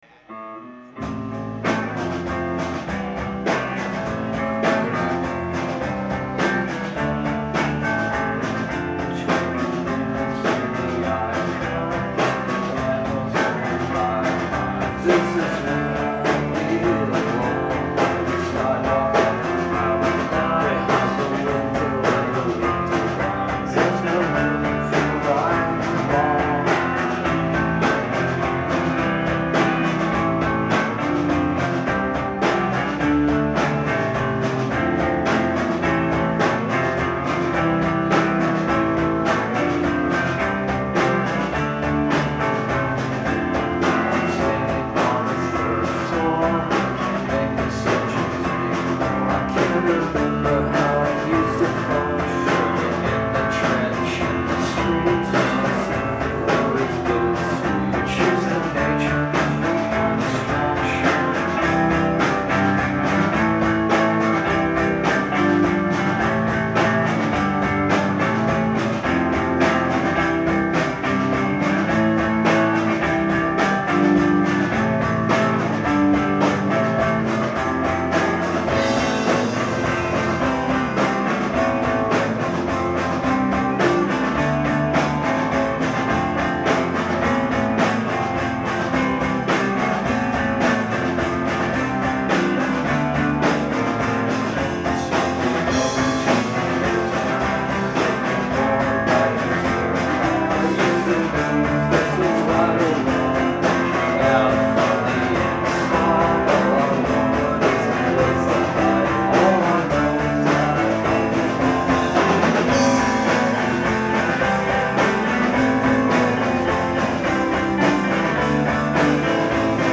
Live at The Middle East Upstairs